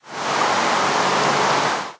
rain3.ogg